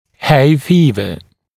[heɪ ‘fiːvə][хэй ‘фи:вэ]сенная лихорадка